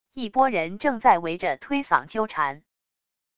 一拨人正在围着推搡纠缠wav音频